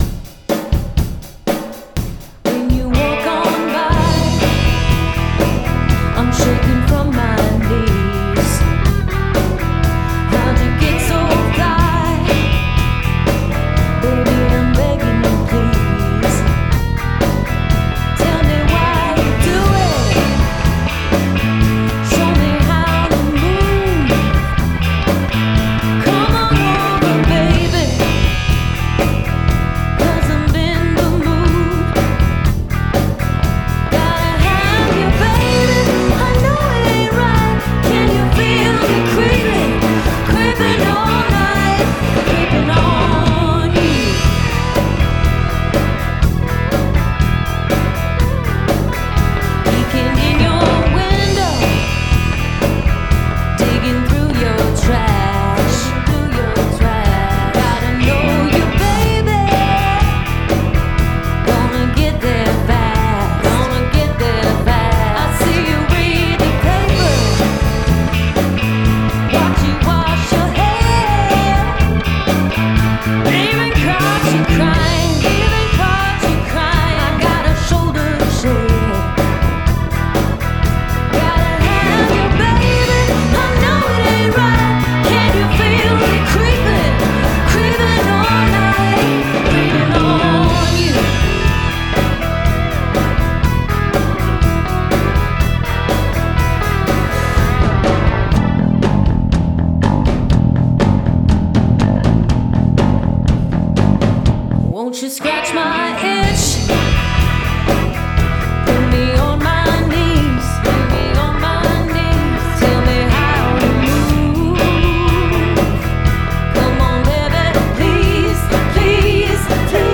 Guitars and Bass
Rock/Bues Pedal Steel
blues mix VOX 4.mp3